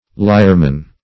lyerman - definition of lyerman - synonyms, pronunciation, spelling from Free Dictionary Search Result for " lyerman" : The Collaborative International Dictionary of English v.0.48: Lyerman \Ly"er*man\, n. (Zool.) The cicada.